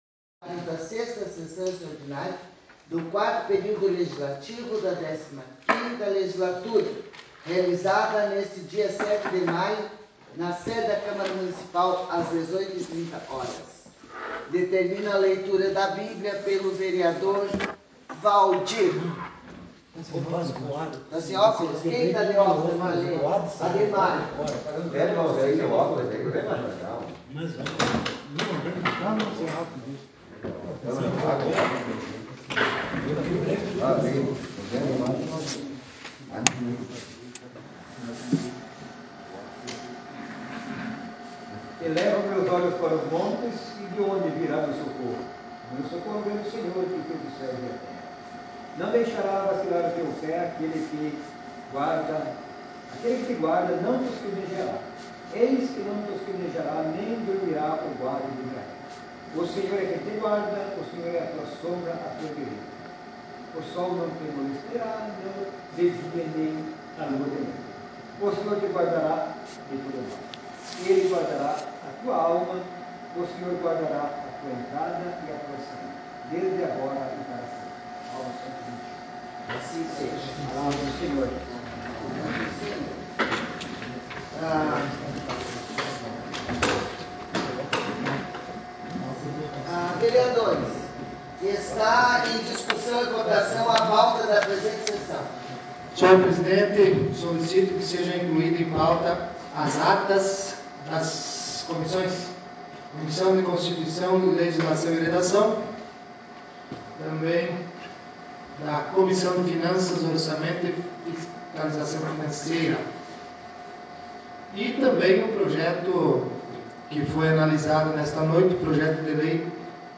Sessão Ordinária 13 de maio de 2020